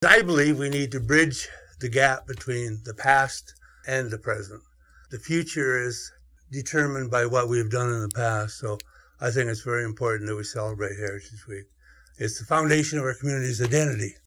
Councillor Bill Martin shared with myFM the display is about understanding where the community came from.